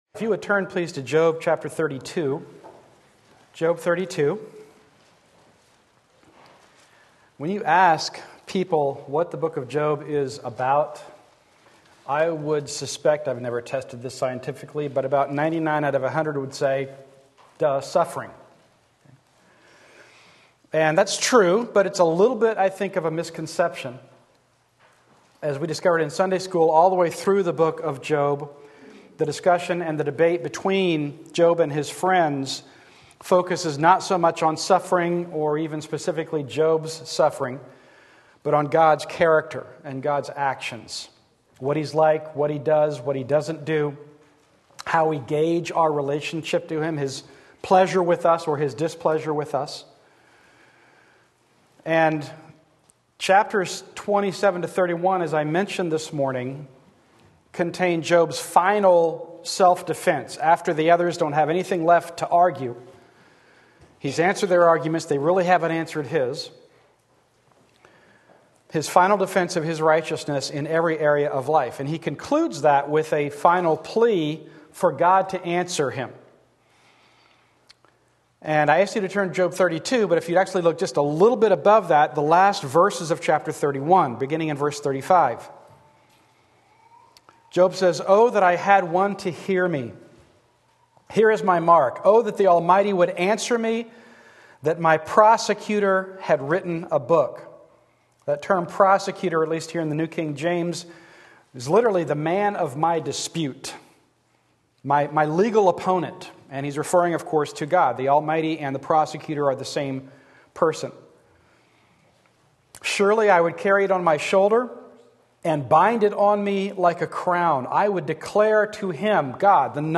Sermon Link
Job Sunday Morning Service